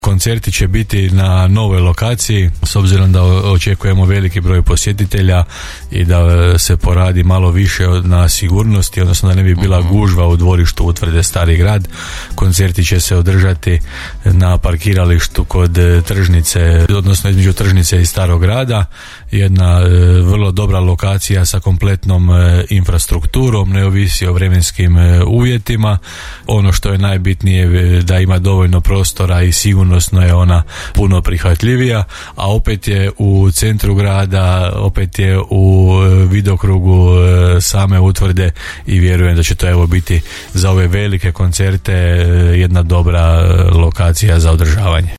U ovotjednoj emisiji Gradske teme u programu Podravskog radija, gost je bio gradonačelnik Hrvoje Janči koji je govorio o nadolazećoj 57. Picokijadi koja će se održati idućeg vikenda od 27. do 29. lipnja 2025. godine.